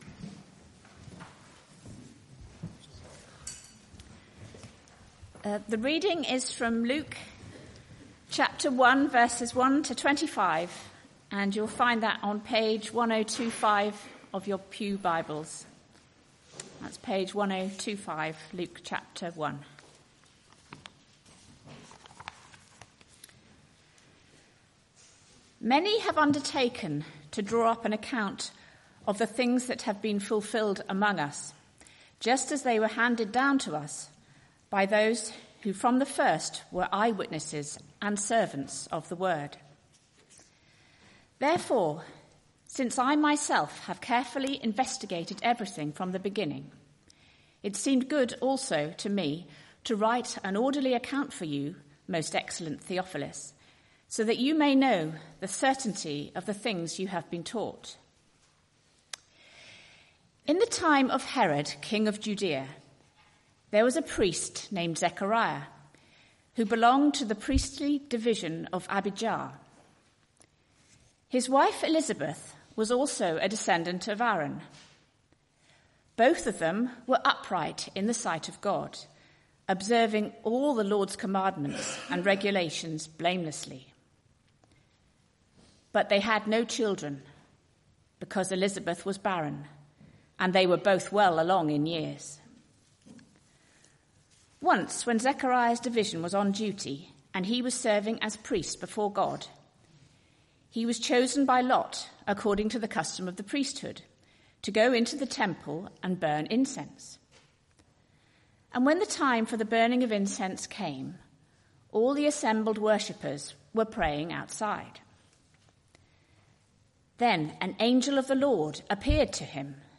Media for Arborfield Morning Service on Sun 03rd Dec 2023 10:00
Theme: Sermon